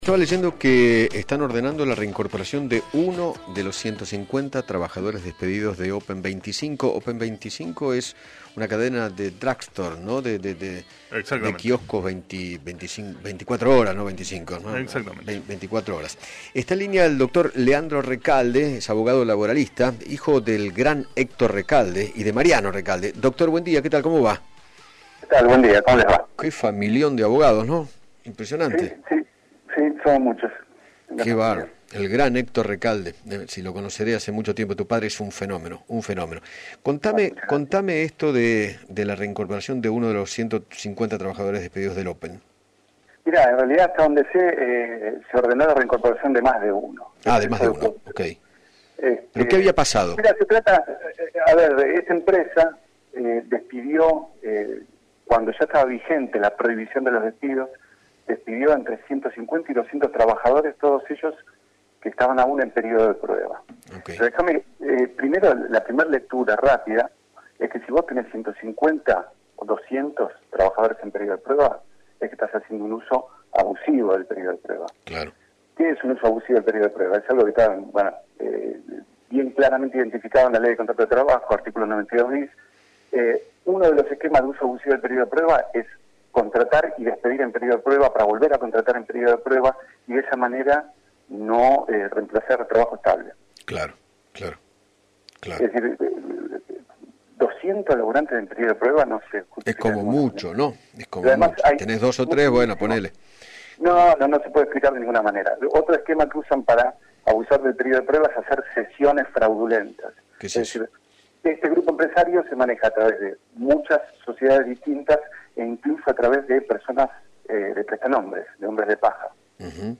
abogado laboralista, dialogó con Eduardo Feinmann sobre la orden de la Cámara de Apelaciones del Trabajo para que la cadena de Kioscos Open 25 Hs reincorpore a más de un empleado de los 150 que fueron despedidos